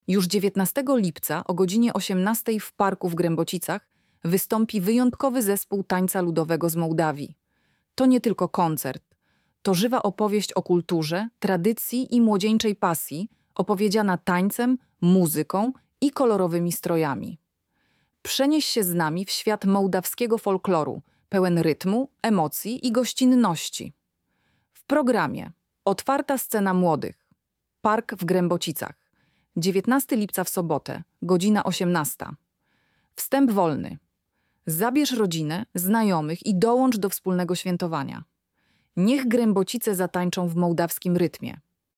Lektor-Moldawia.mp3